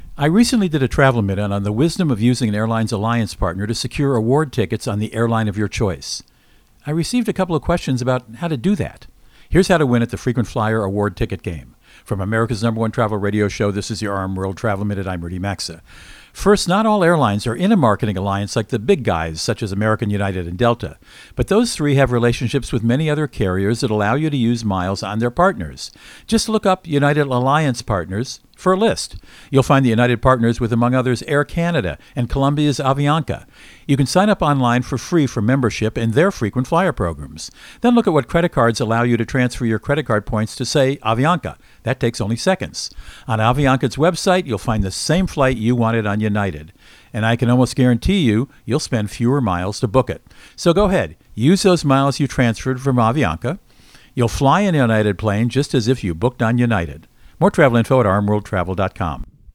Co-Host Rudy Maxa | Frequent Flyer Ticket Game Winners